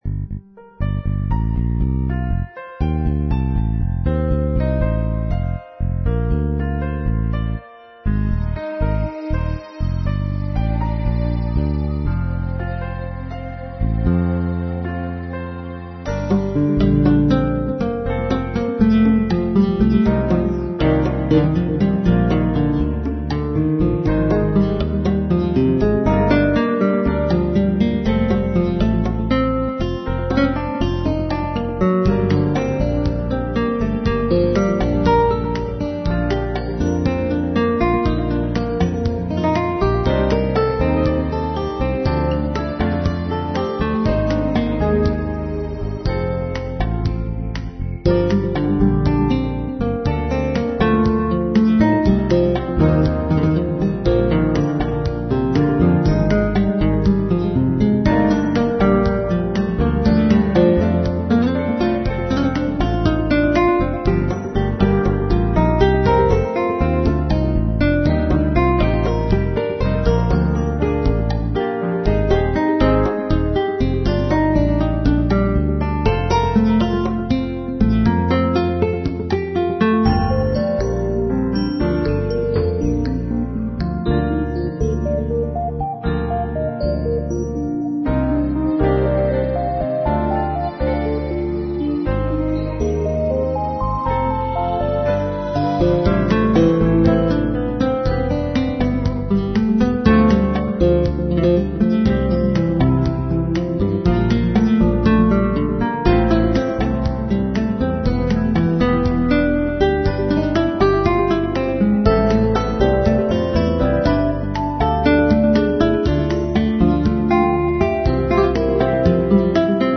Relaxed soundtrack music with classic Guitar lead